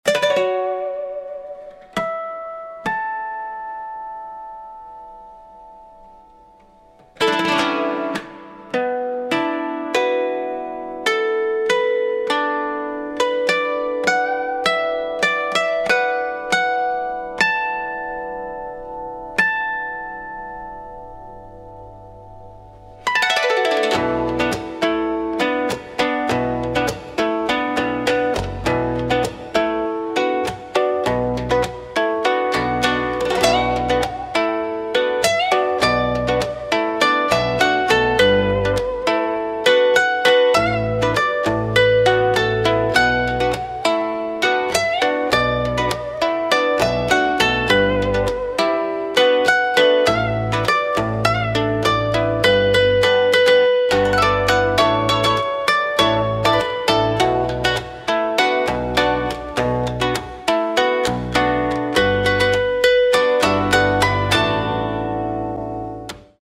Cítara japonesa